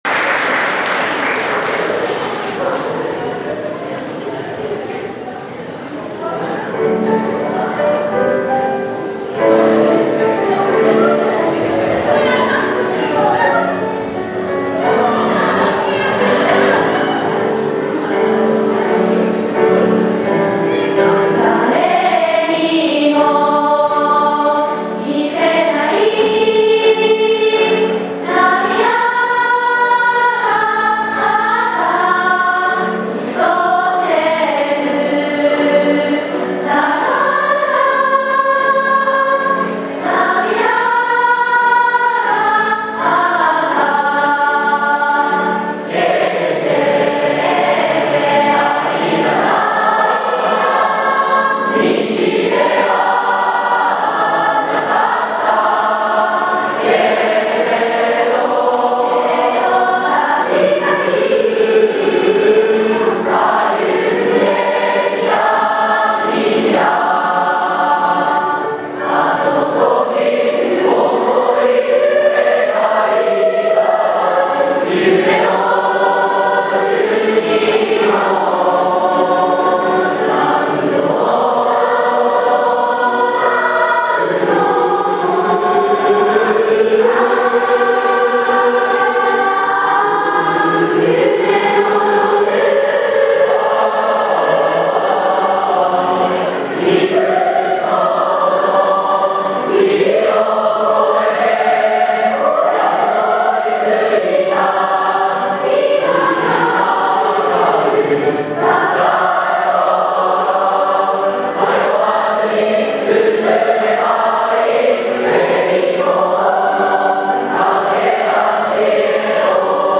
3年生を送る会(3月10日)
5時間目に送る会を行いました。 在校生の合唱や、劇で、3年生も楽しんでいました。